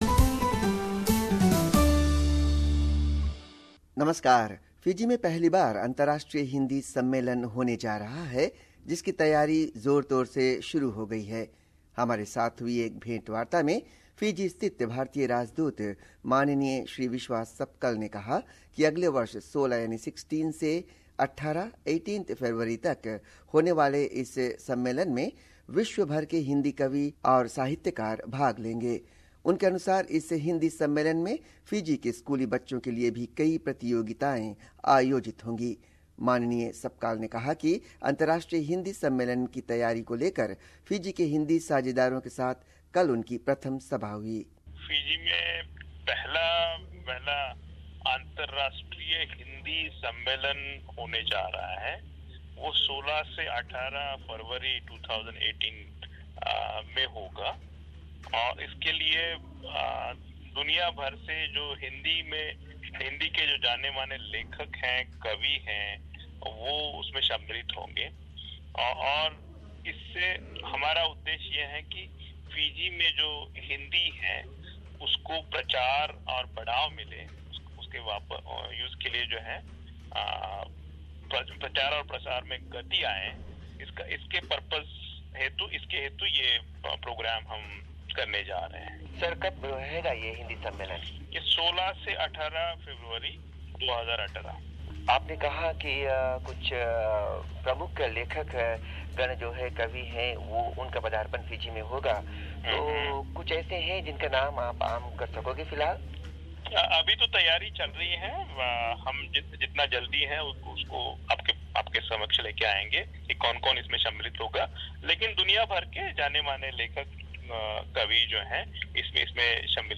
First ever International Hindi conference would be held in Fiji from 16th to 18th Feb 2018. Indian Ambassador to Fiji, Hon. Vishwas Sapkal speaks